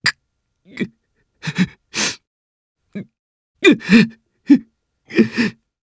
Clause-Vox_Sad_jp.wav